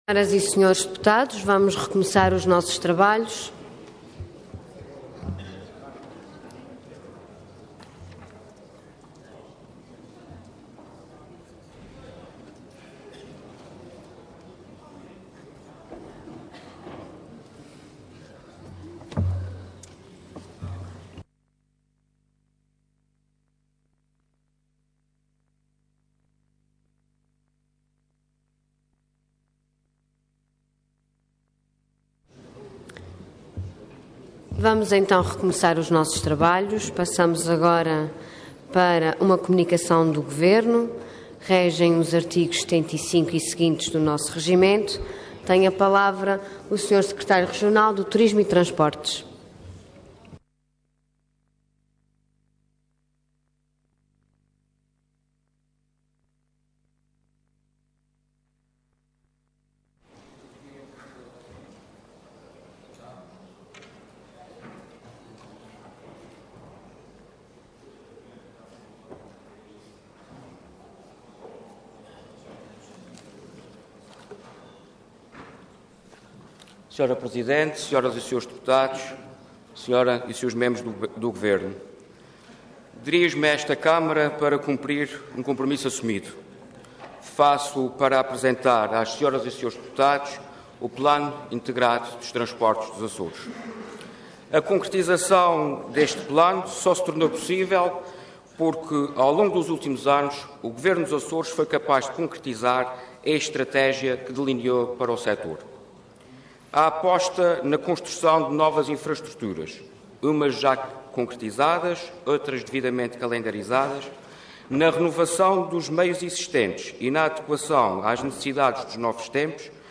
Detalhe de vídeo 11 de março de 2014 Download áudio Download vídeo X Legislatura Plano Integrado dos Transportes dos Açores Intervenção Comunicação do Governo Orador Vítor Ângelo de Fraga Cargo Secretário Regional do Turismo e Transportes Entidade Governo